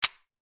Divergent / mods / JSRS Sound Mod / gamedata / sounds / weapons / _dryfire / 545_dry.ogg
545_dry.ogg